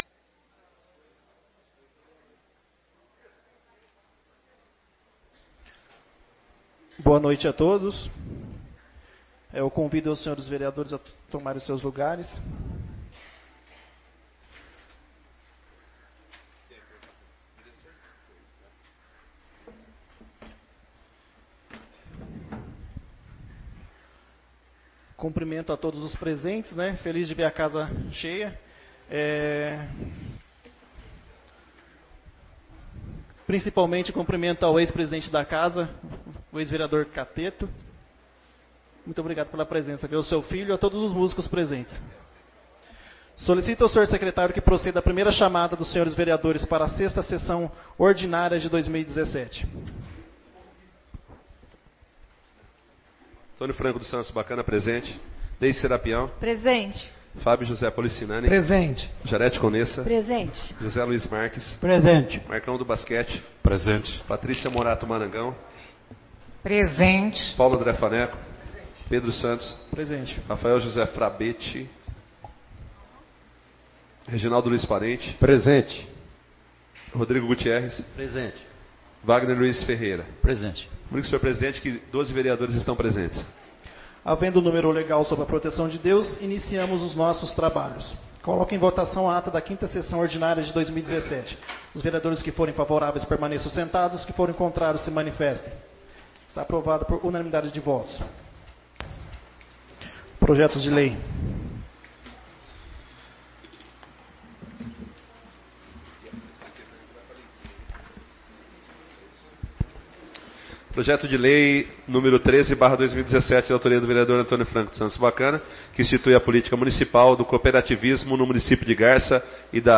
6ª Sessão Ordinária de 2017